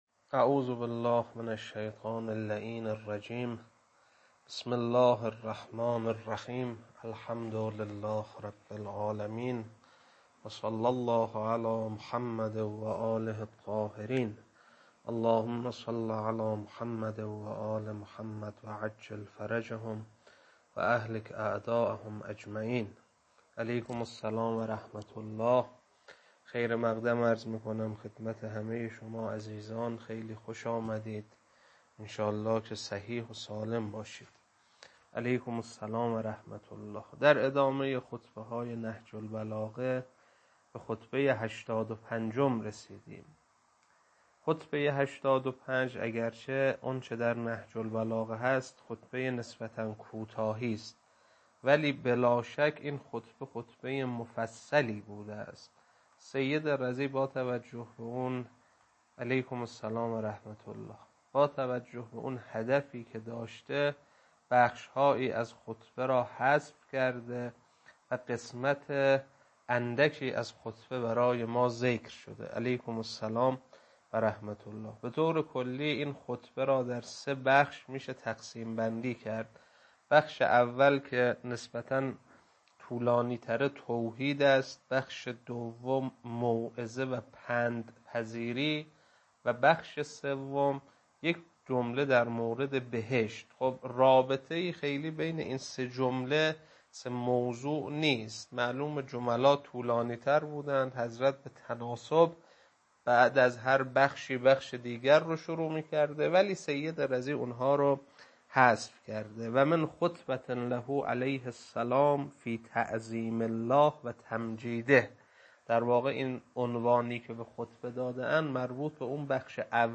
خطبه-85.mp3